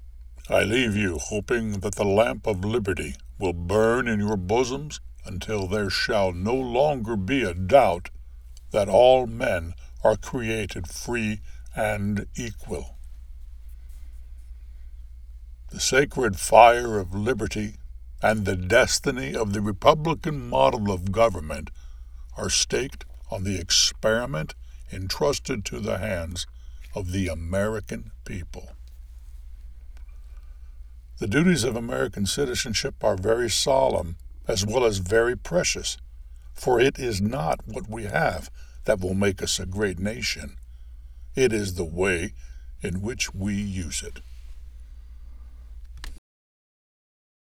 VO Sample
Senior